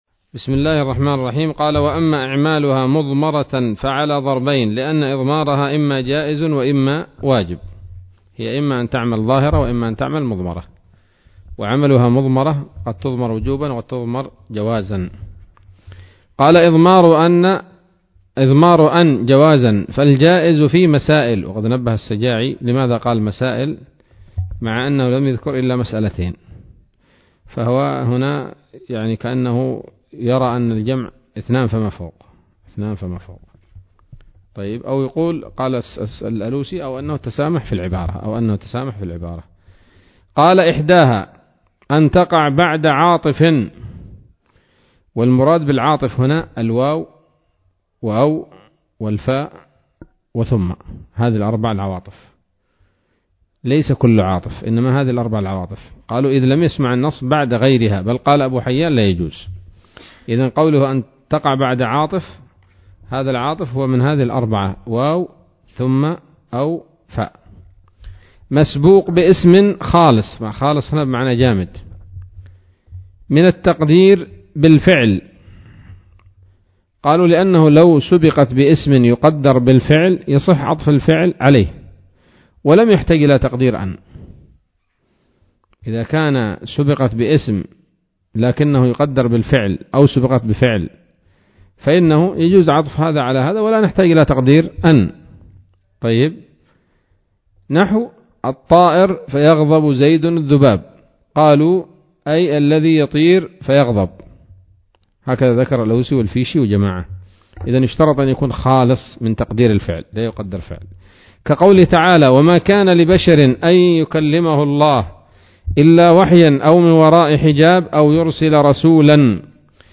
الدرس الثلاثون من شرح قطر الندى وبل الصدى [1444هـ]